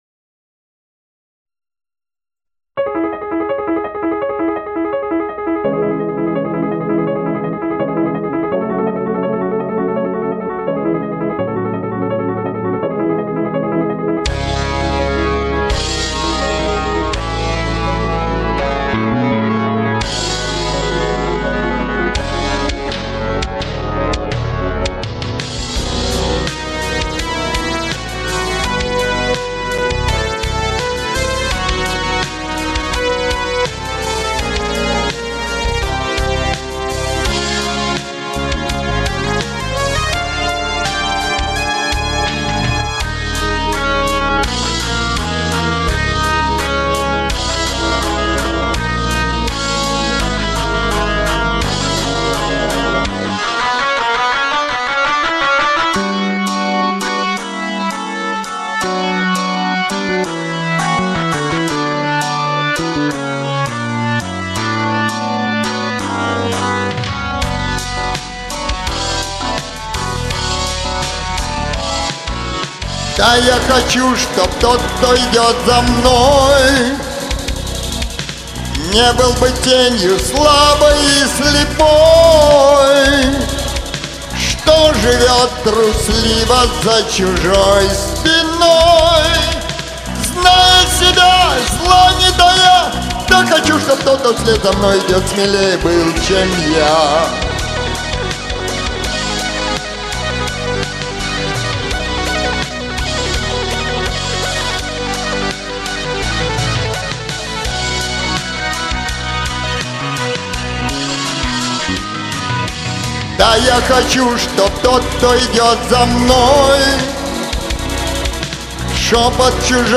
В вокалах и там, и там есть на мой слух неровности.